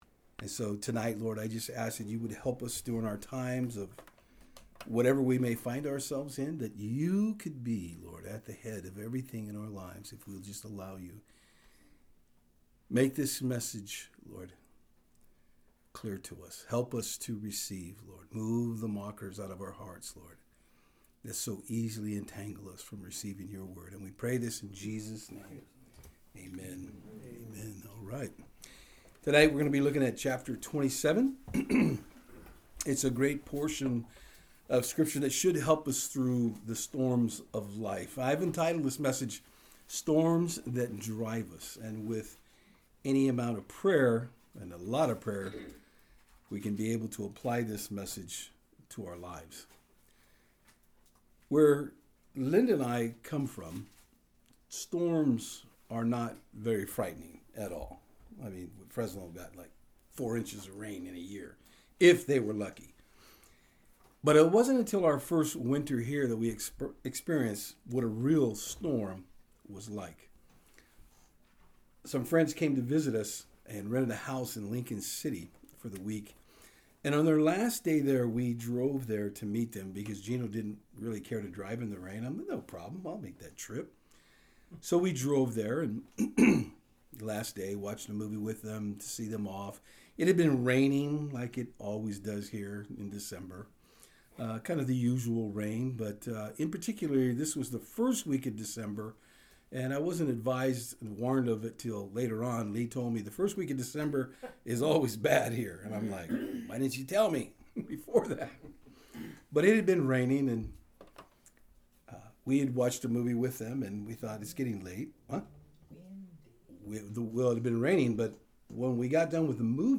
Passage: Acts 27:1-21 Service Type: Saturdays on Fort Hill